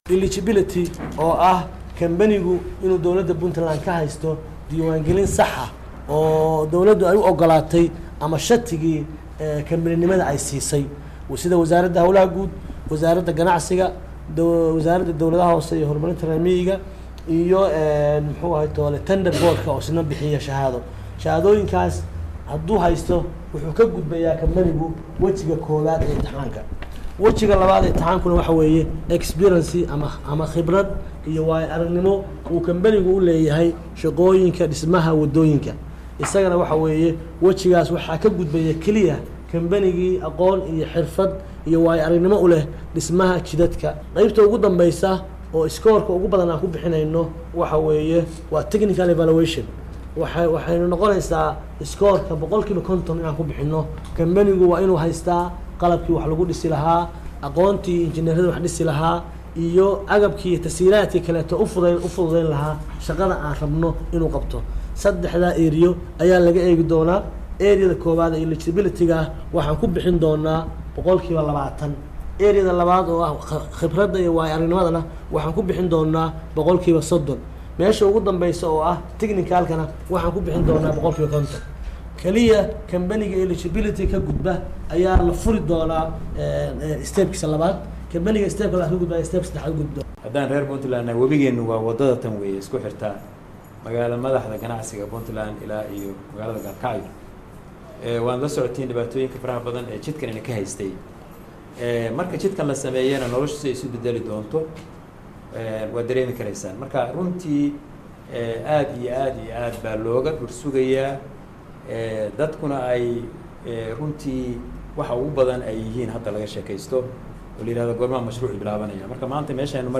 Dhagayso cododka Agaasimaha Hay,ada wadooyinka Puntland & Wasiirida